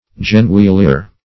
Search Result for " genouillere" : The Collaborative International Dictionary of English v.0.48: Genouillere \Ge*nouil`l[`e]re"\, n. [F.] 1.